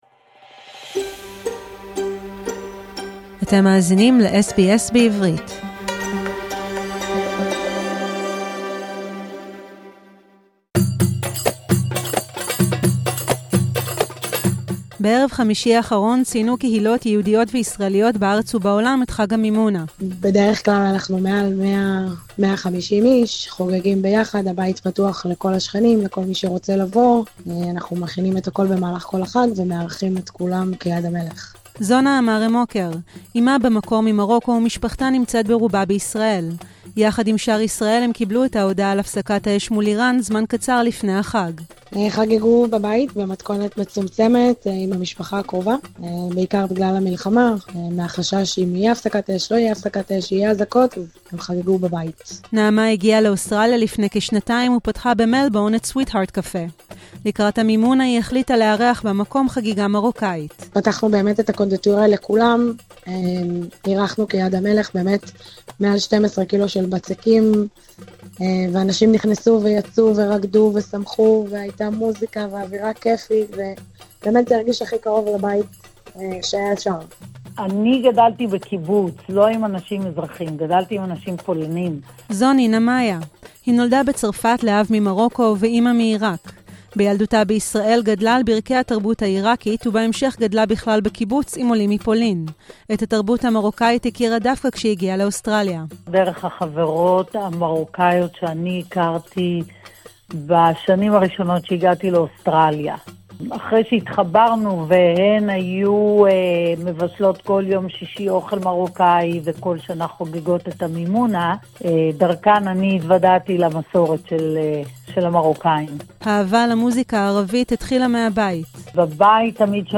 לרגל חג המימונה ארבעה אנשים מתוך הקהילה היהודית ישראלית באוסטרליה שיתפו אותנו בחיבור שלהם לחג -